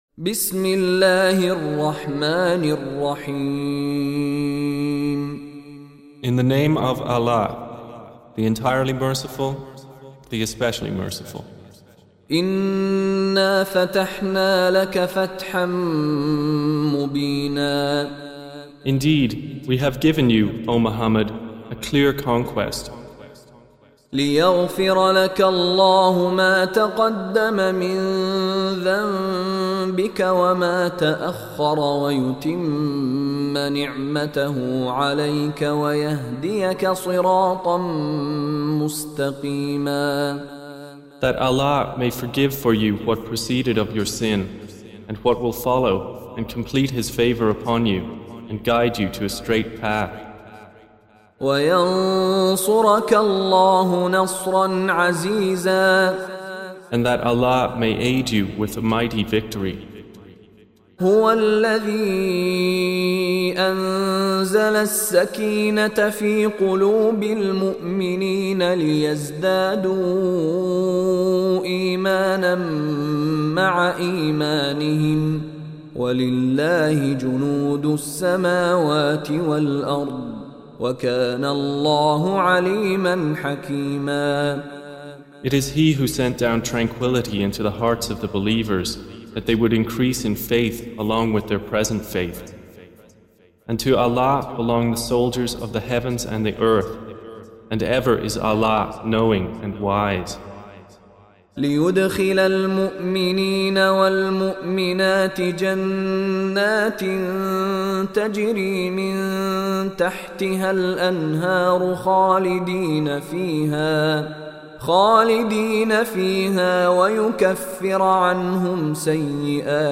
48. Surah Al-Fath سورة الفتح Audio Quran Tarjuman Translation Recitation